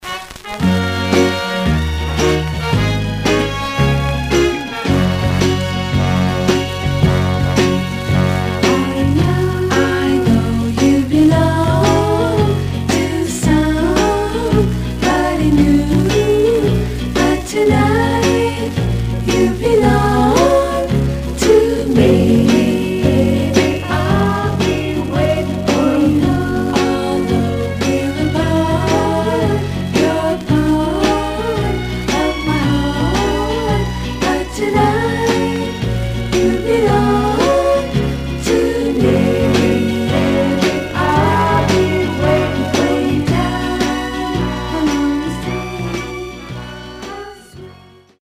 Some surface noise/wear Stereo/mono Mono
White Teen Girl Groups